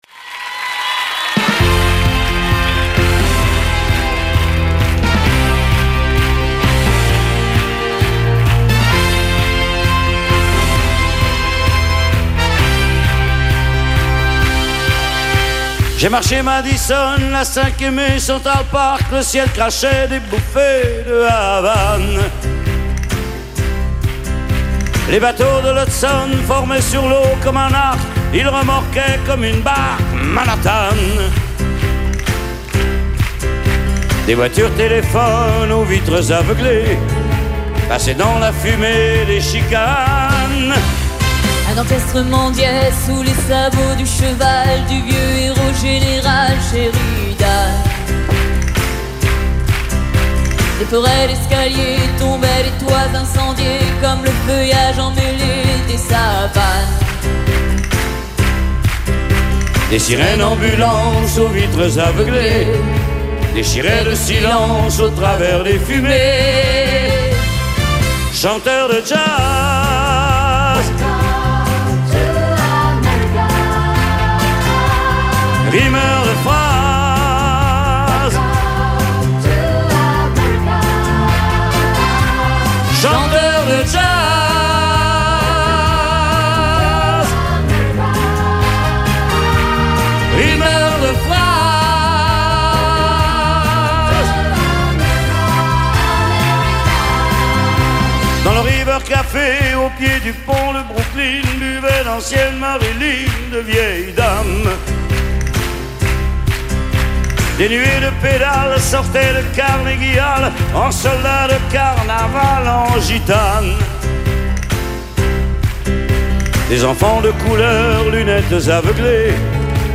DUOS